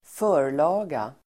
Uttal: [²f'ö:r_la:ga]